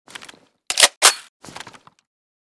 protecta_unjam.ogg.bak